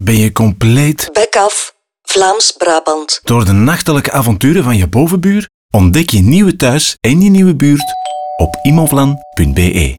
Radiospot_Immovlan_Bekaf